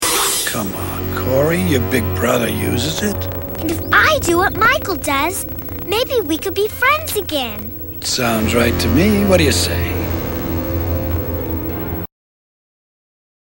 animated marijuana smoke voiced by Ed Asner George C. Scott in after-school specials.
ed-asner-pot-smoke.mp3